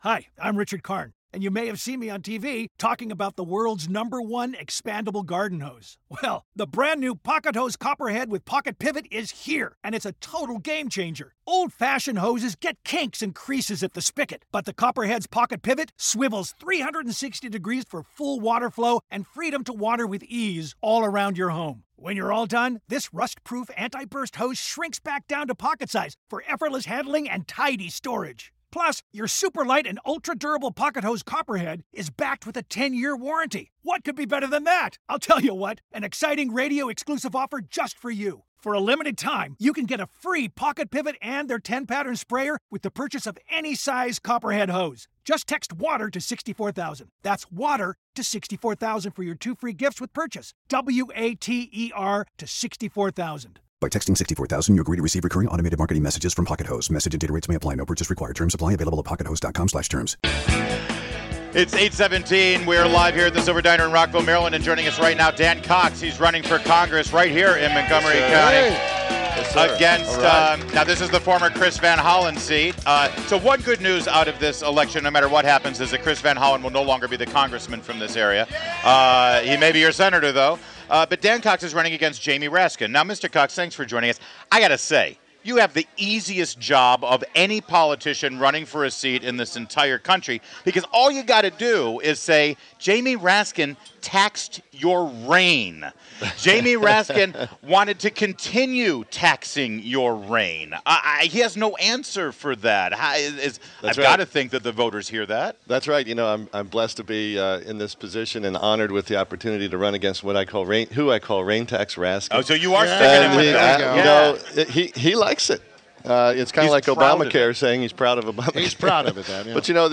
INTERVIEW – DAN COX – Republican candidate for Maryland’s 8th Congressional District